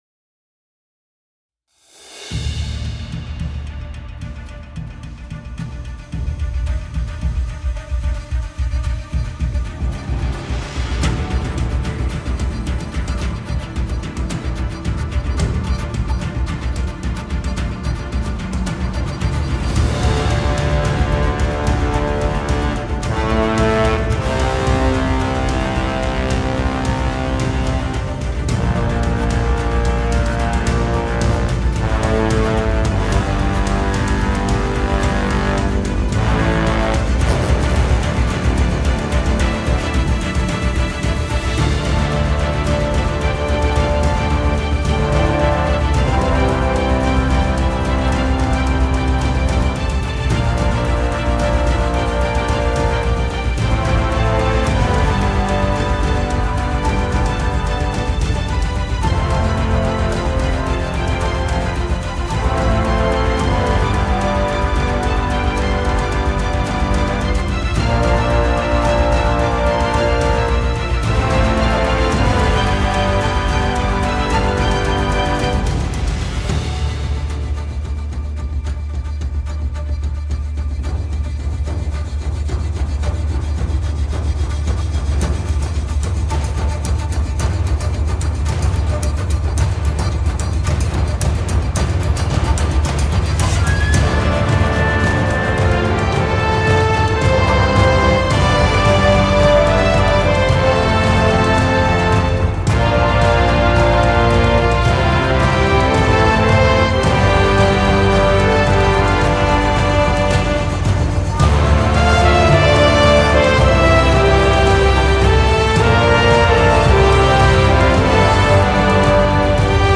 music for Reality TV